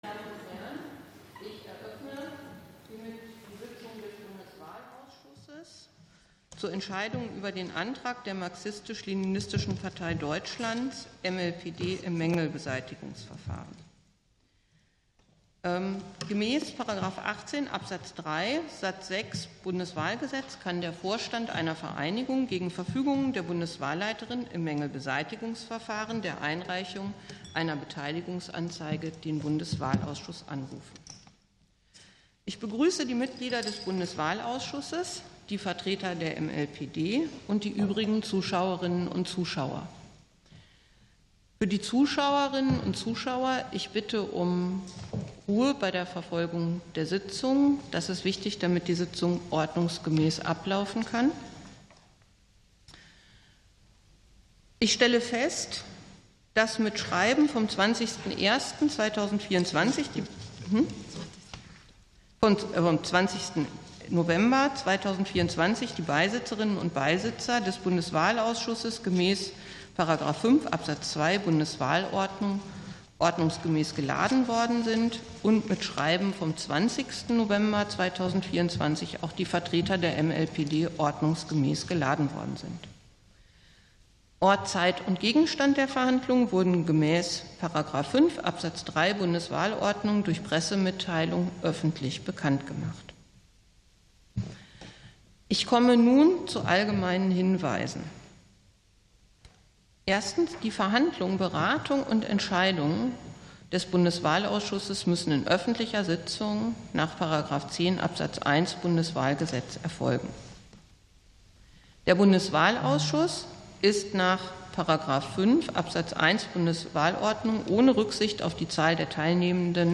Ausschusssitzungen - Audio Podcasts